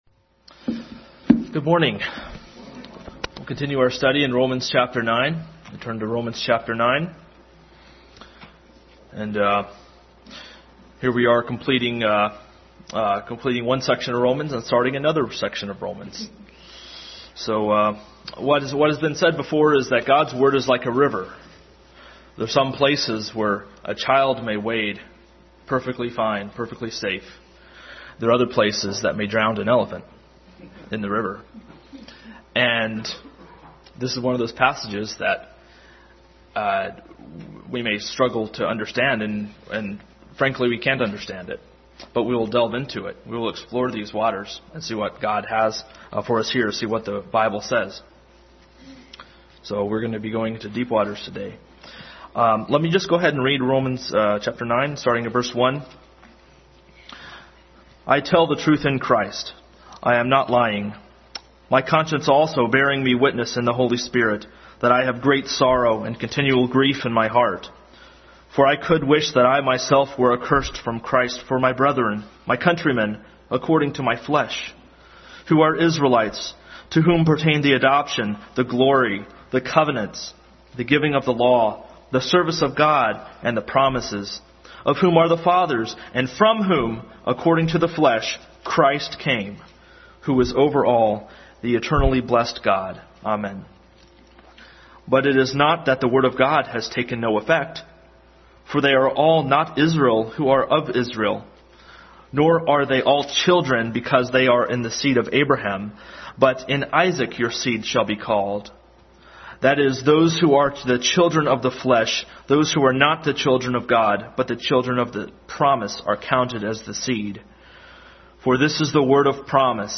Adult Sunday School Class continued study in Romans.